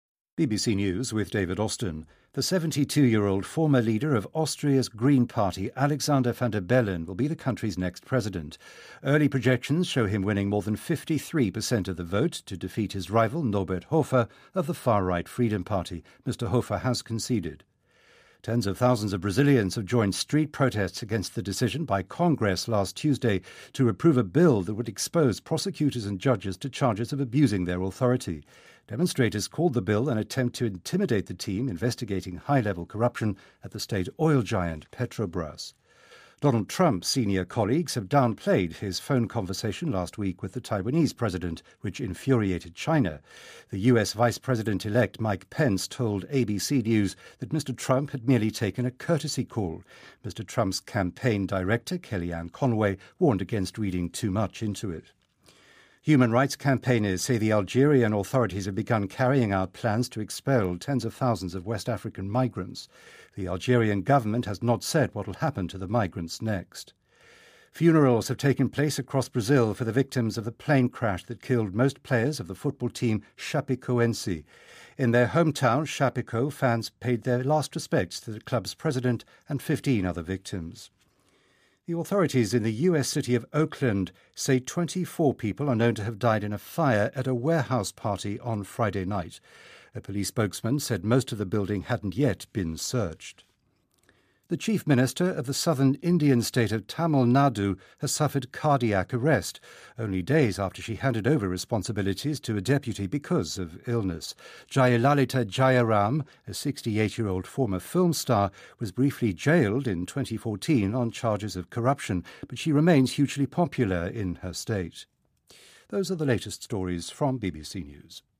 您的位置：BBC > BBC在线收听 > 12月新闻 > 巴西各地为飞机失事遇难者举行葬礼